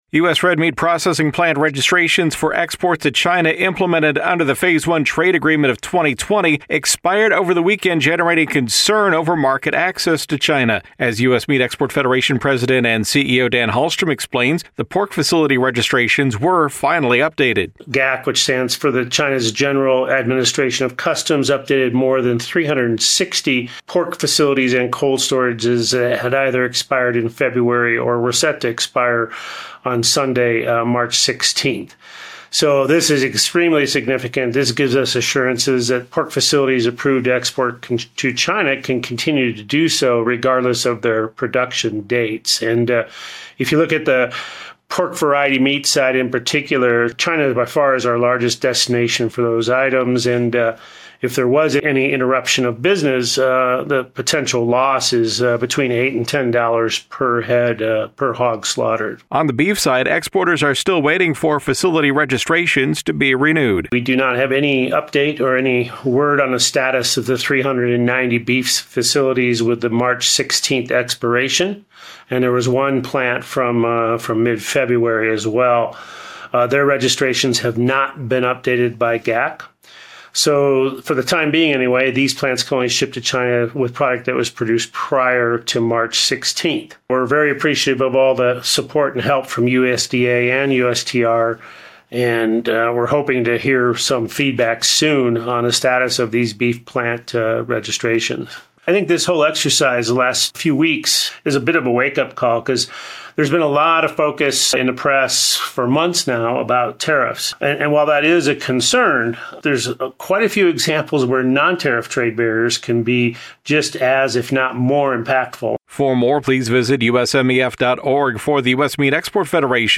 USMEF Weekly Audio Report: Progress on Pork Facility Registrations for China